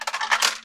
barn_coin_enter.ogg